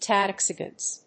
音節Ta・dzhik・i・stan 発音記号・読み方
/tɑːdʒìkɪstˈæn(米国英語)/